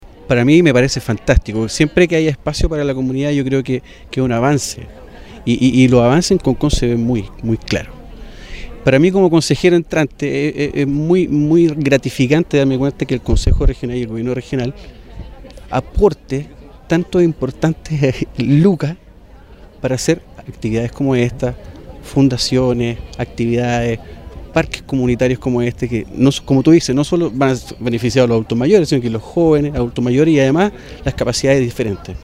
Con una tradicional ceremonia, se le dio el vamos oficial a la construcción del futuro Parque Comunitario de Concón.